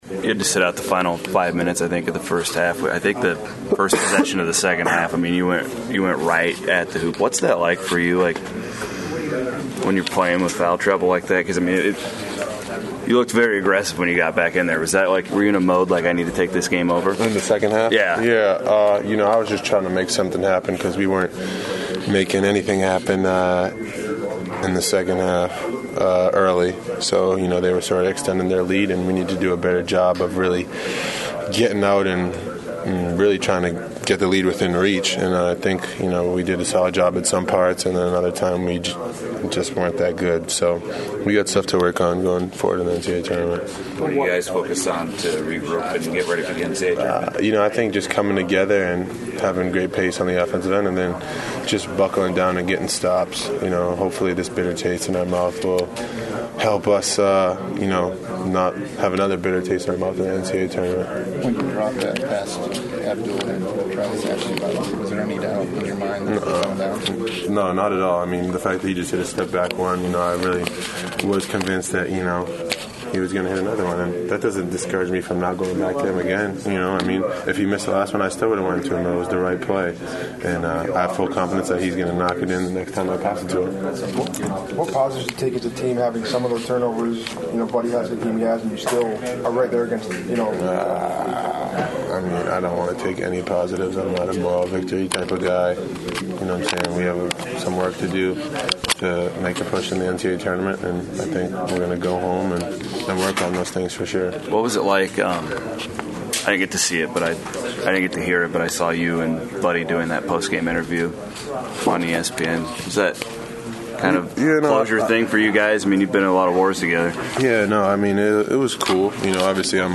KANSAS CITY — Following Iowa State’s to loss to Oklahoma on Thursday night in the Big 12 Tournament, I caught up with Georges Niang in the locker room for this short one-on-one conversation. We talk about the loss and more importantly, this year’s NCAA Tournament.